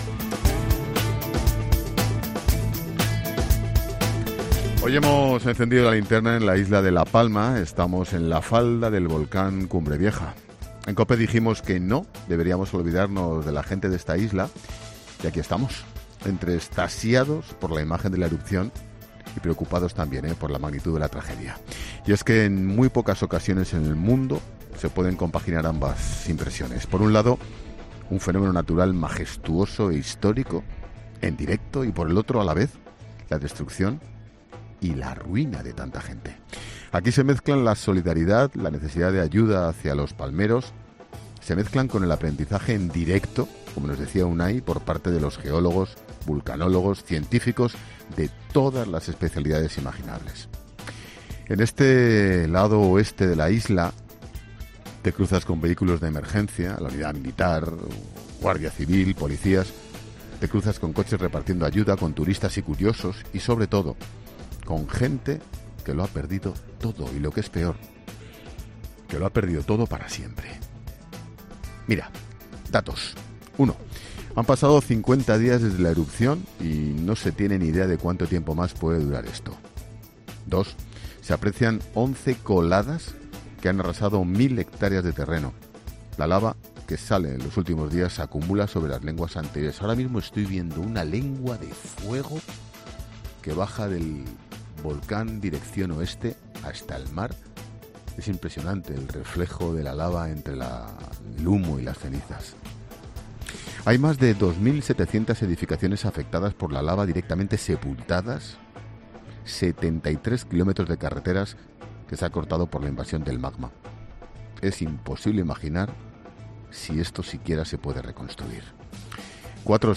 Monólogo de Expósito
Hoy hemos encendido 'La Linterna' en la isla de La Palma. Estamos en la falda del volcán Cumbre Vieja.